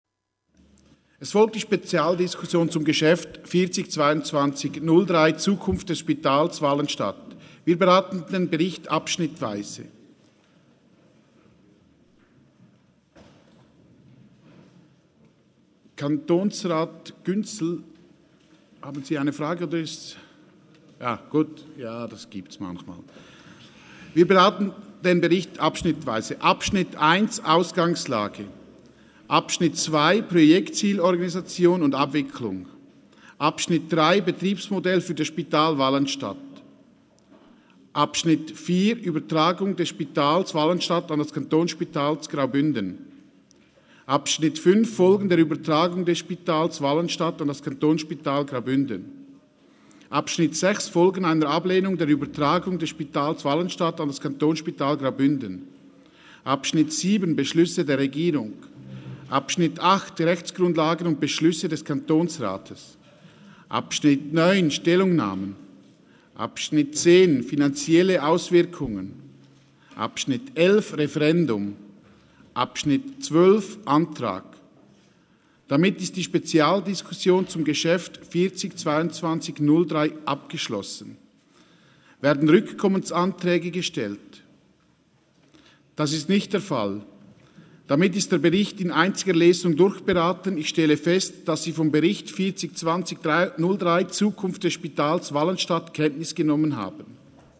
13.6.2022Wortmeldung
Session des Kantonsrates vom 13. bis 15. Juni 2022